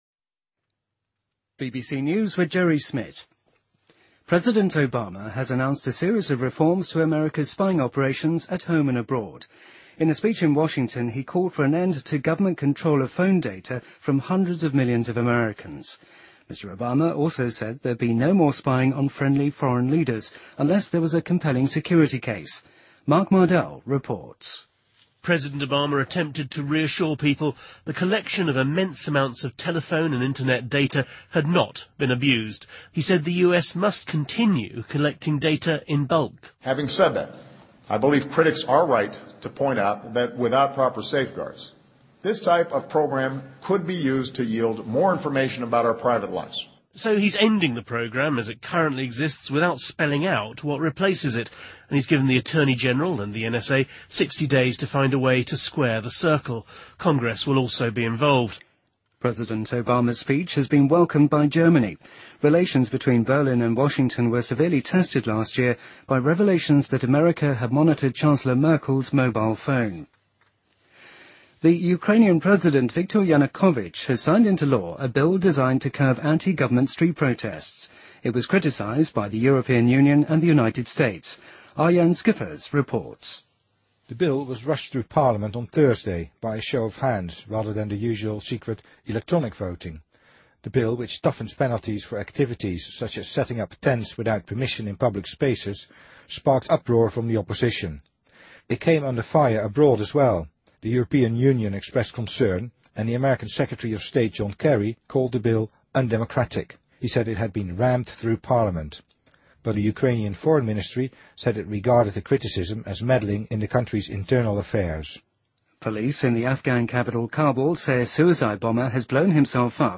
BBC news,2014-01-18